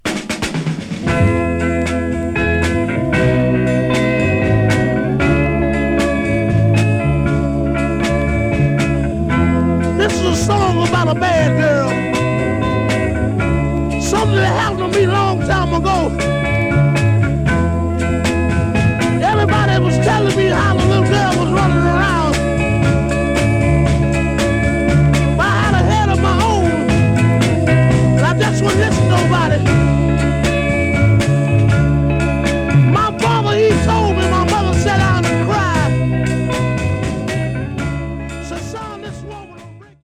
Genre: Funk/Soul, Soul